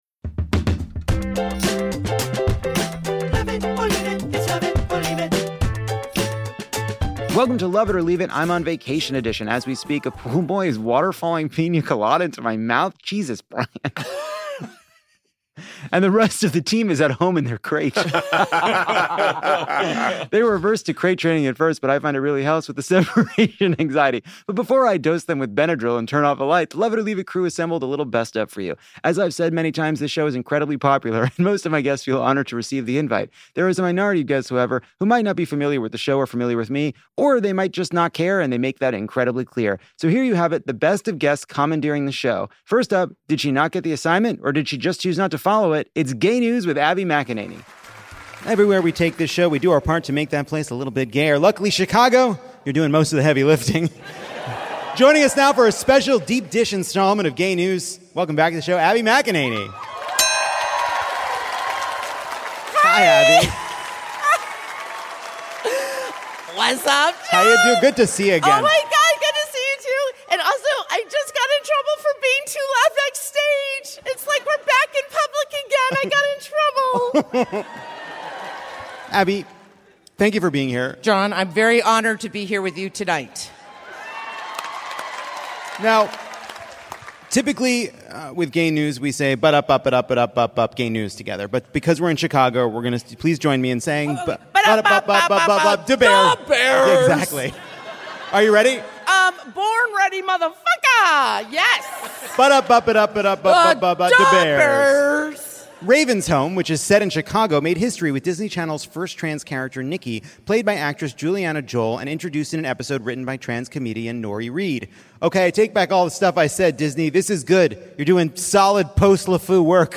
Abby McEnany commandeers the anchor’s seat while presenting Gay News.
Luenell riles up the Swifties, and Ms. Pat challenges our audience to answer two truths and a lie, all while Lovett holds on for dear life.